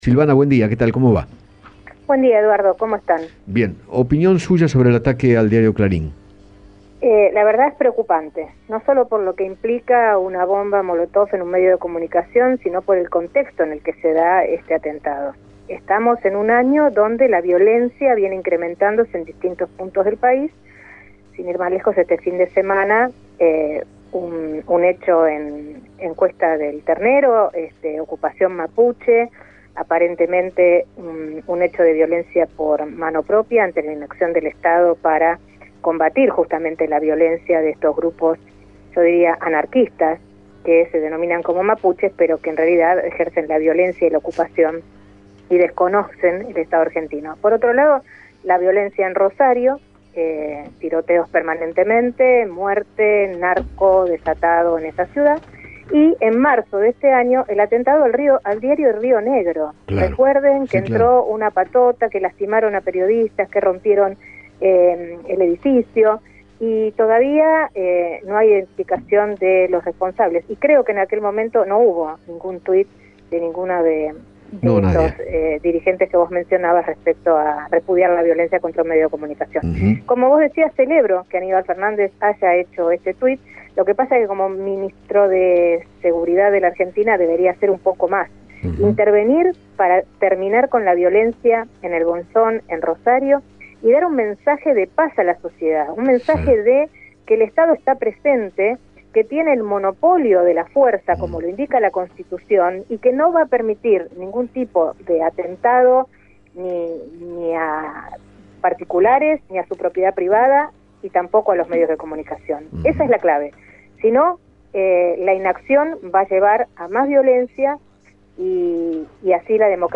Silvana Giudici, presidenta de la Fundación LED, conversó con Eduardo Feinmann sobre el ataque con bombas molotov al edificio del grupo Clarín y consideró que “está en peligro la paz social”.